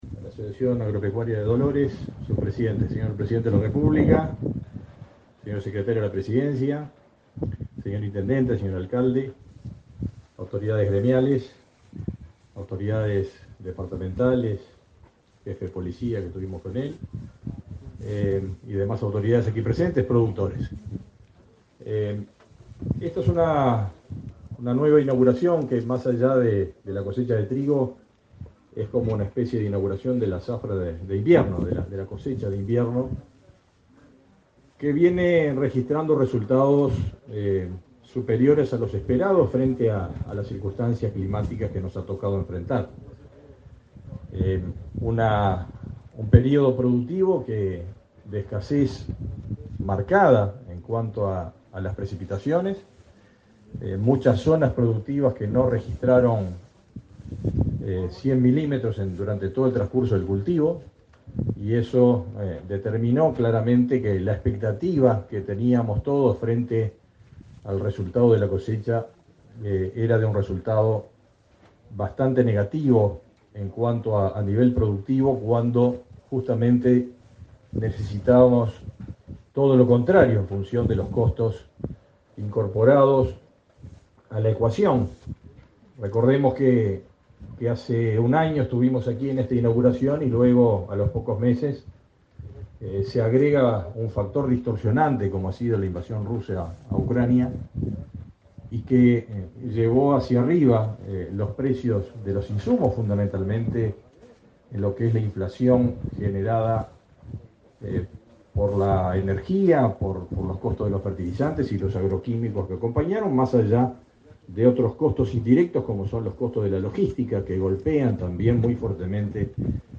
Conferencia de prensa por la inauguración de la cosecha de trigo
Conferencia de prensa por la inauguración de la cosecha de trigo 15/11/2022 Compartir Facebook X Copiar enlace WhatsApp LinkedIn Con la presidencia del presidente de la República, Luis Lacalle Pou, este 15 de noviembre, en la ciudad de Dolores, se realizó la inauguración de la cosecha de trigo.